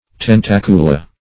Tentacula - definition of Tentacula - synonyms, pronunciation, spelling from Free Dictionary